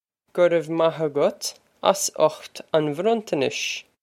Pronunciation for how to say
Guh rev mah a-gut oss ut on vrun-ton-nish.
This is an approximate phonetic pronunciation of the phrase.